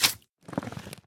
Sound / Minecraft / mob / magmacube / jump2.ogg
should be correct audio levels.